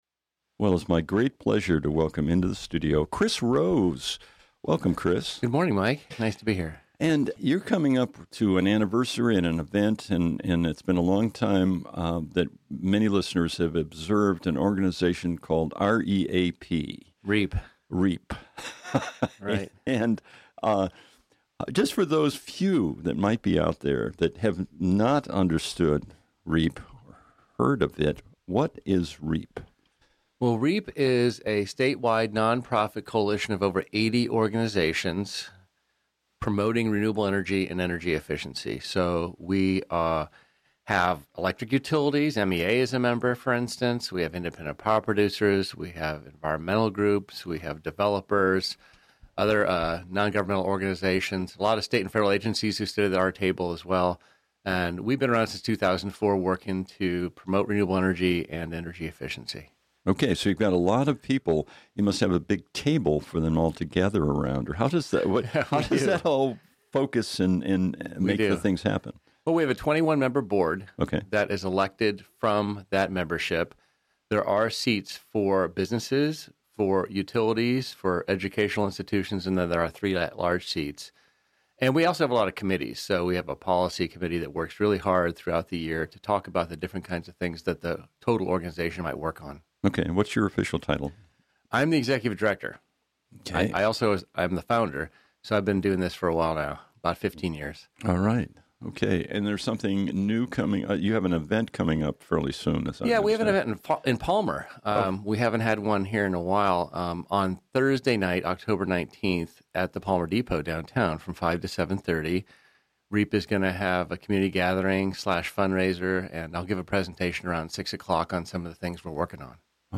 Oct 16, 2017 | Featured, Morning Show Interviews